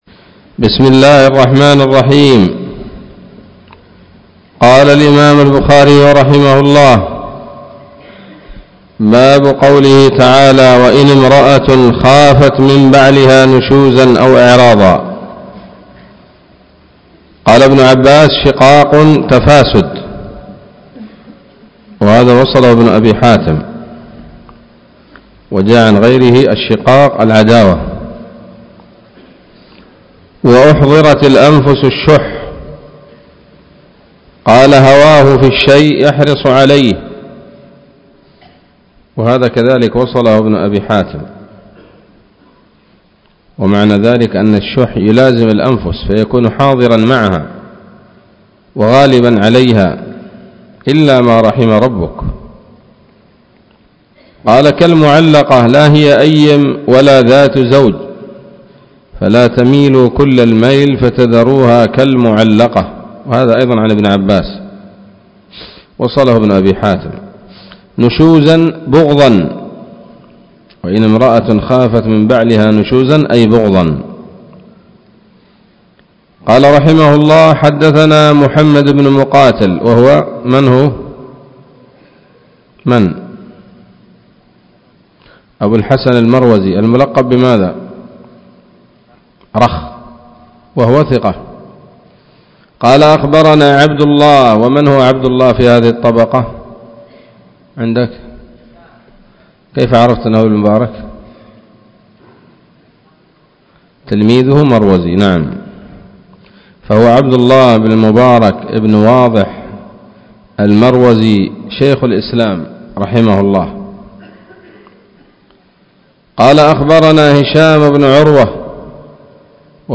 الدرس الثاني والثمانون من كتاب التفسير من صحيح الإمام البخاري